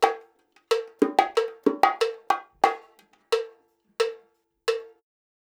90 BONGO 5.wav